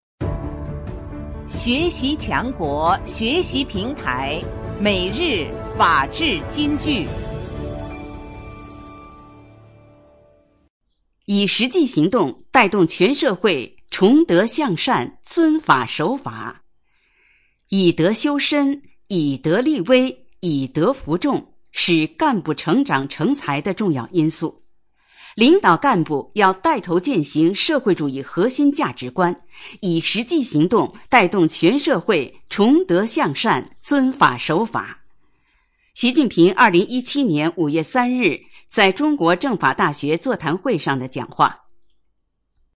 每日法治金句（朗读版）|以实际行动带动全社会崇德向善、尊法守法 _ 学习宣传 _ 福建省民政厅